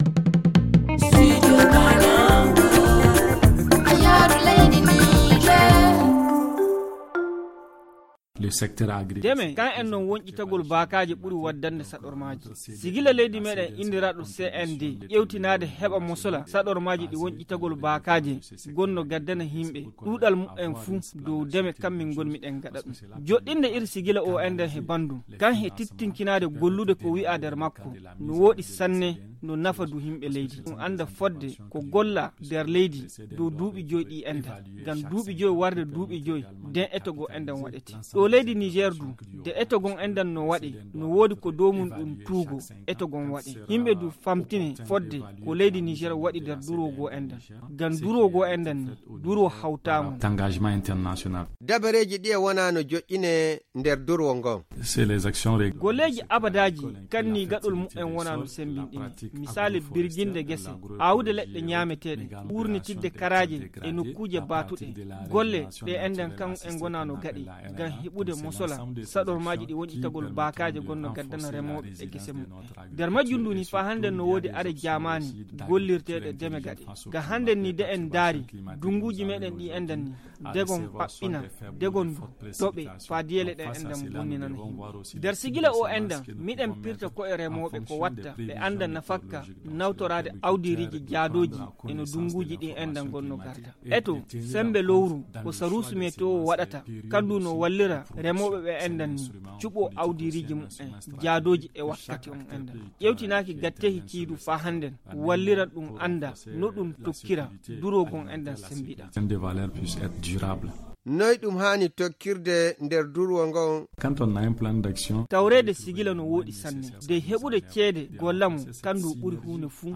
Le magazine en fulfuldé